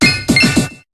Cri d'Archéomire dans Pokémon HOME.